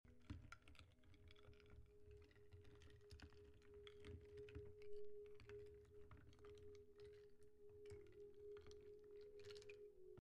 🪵 Wood soup 🥣 sound effects free download